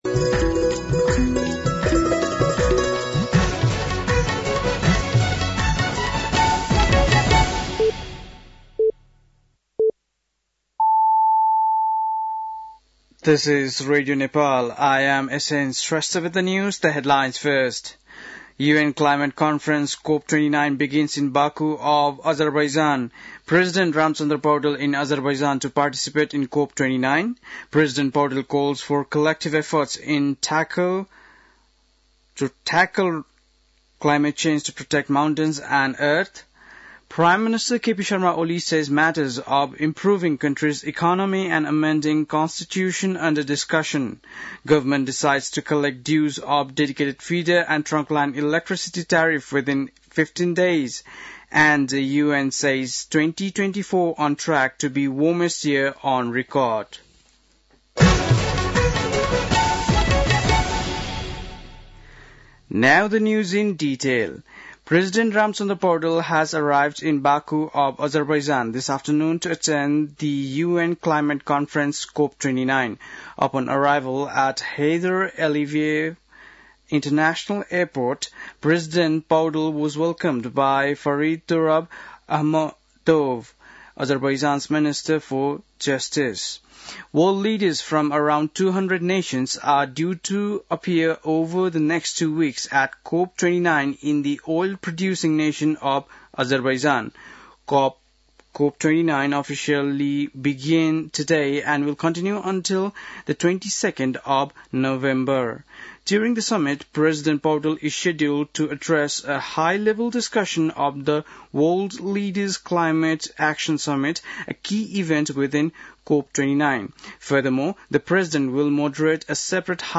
बेलुकी ८ बजेको अङ्ग्रेजी समाचार : २७ कार्तिक , २०८१
8-pm-NEWS-7-26.mp3